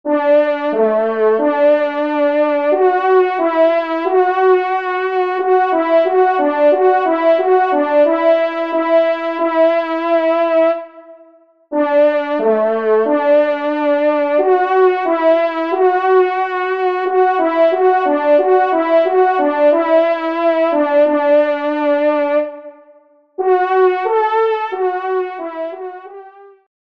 Genre : Divertissement
Extrait de l’audio « Ton de Vènerie »